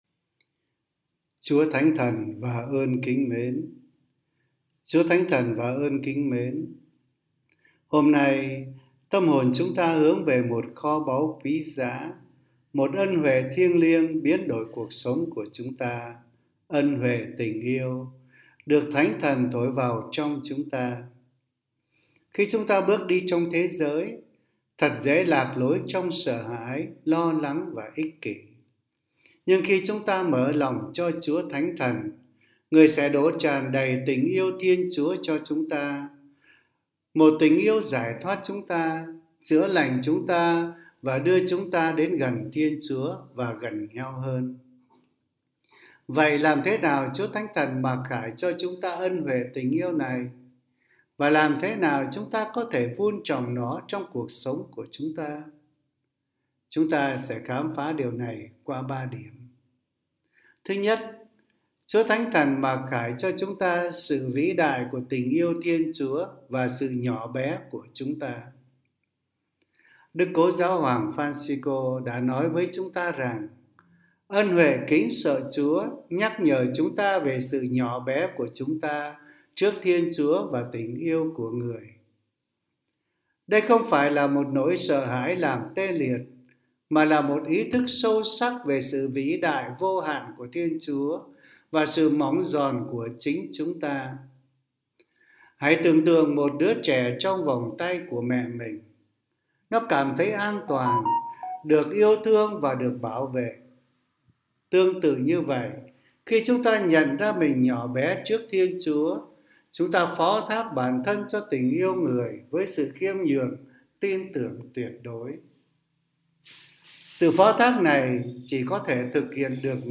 Suy niệm hằng ngày